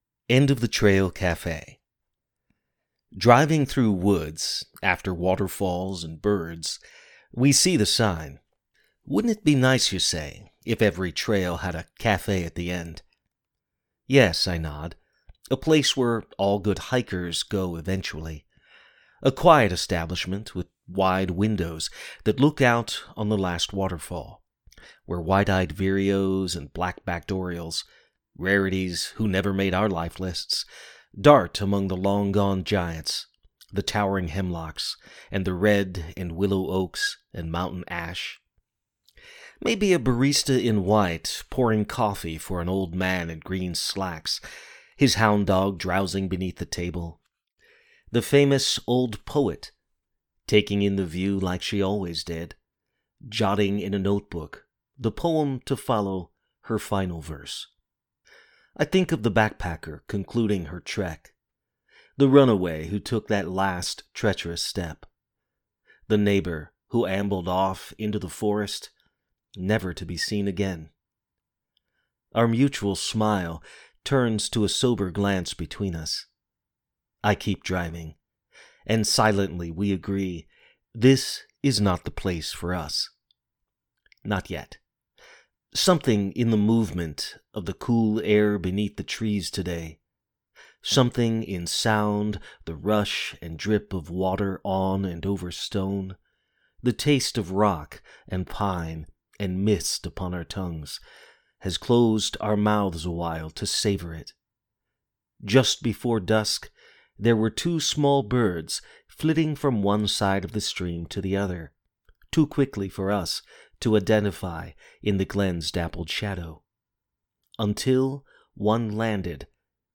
It reads aloud quite nicely too.
Your reading of End of the Trail is so clear, and paced so well.